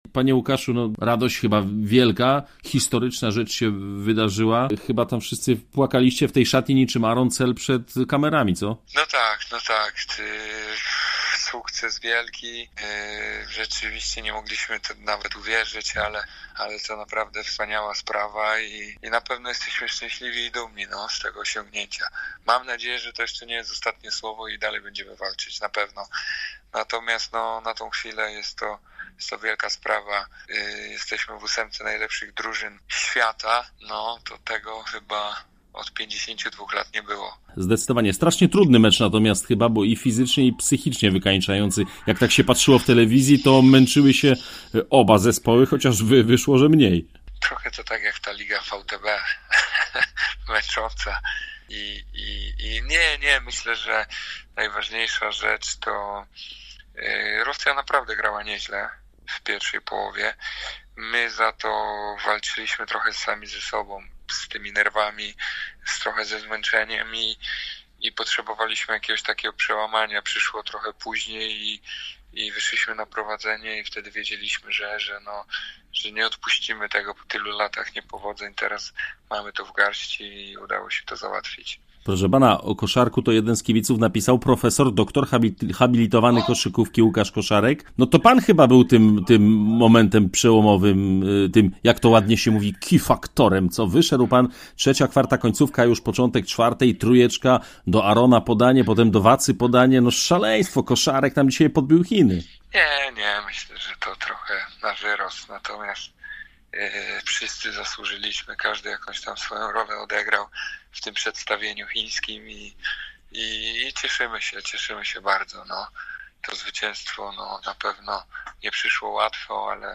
Radiu Zielona Góra udało się skontaktować wieczorem z Koszarkiem i pogadaliśmy o sukcesie jego i reprezentacji: